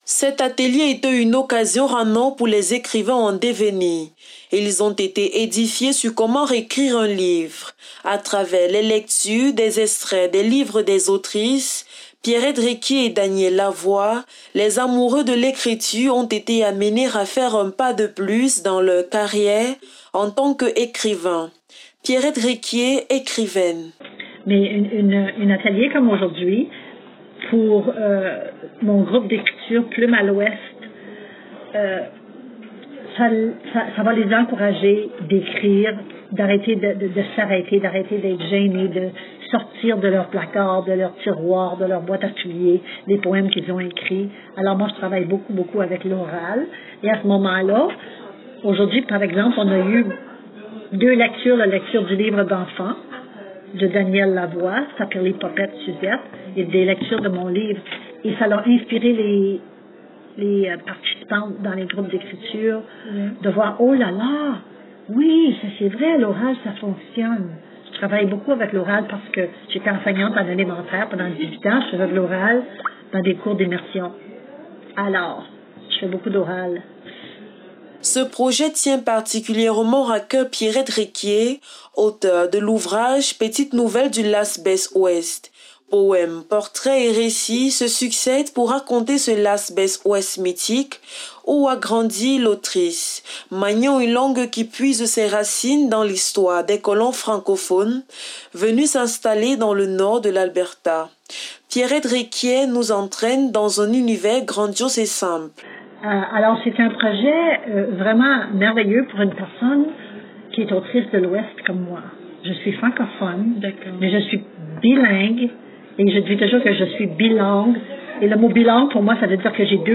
Les précisions dans ce reportage: